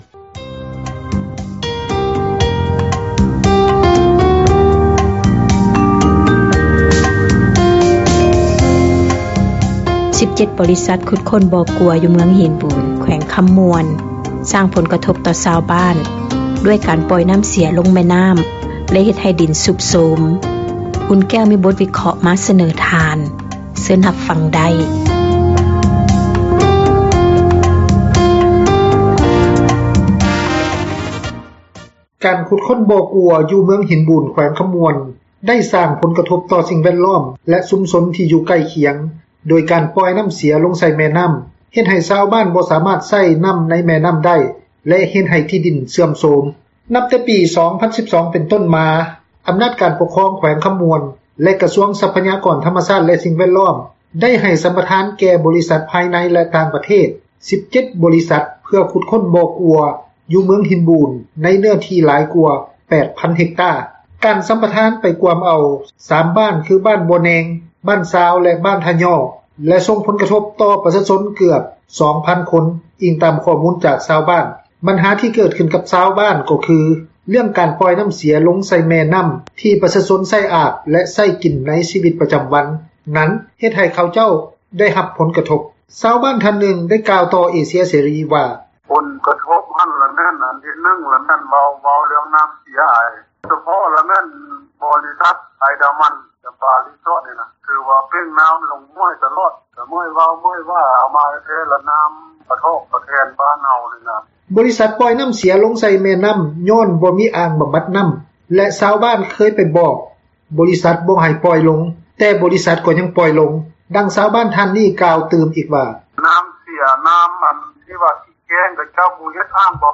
ບັນຫາທີ່ເກີດຂຶ້ນກັບຊາວບ້ານ ກໍຄື, ເລື້ອງການປ່ອຍນ້ຳ ເສັຽລົງໃສ່ ແມ່ນ້ຳທີ່ປະຊາຊົນໃຊ້ອາບ ແລະ ໃຊ້ກິນໃນຊີວິດ ປະຈຳວັນນັ້ນ ເຮັດໃຫ້ເຂົາເຈົ້າໄດ້ຮັບຜົນກະທົບ. ຊາວບ້ານທ່ານນຶ່ງ ໄດ້ກ່າວຕໍ່ເອເຊັຽເສຣີ ວ່າ:
ຊ່ຽວຊານດ້ານກົດໝາຍທ່ານນຶ່ງ ໄດ້ກ່າວຕໍ່ເອເຊັຽເສຣີ ວ່າ: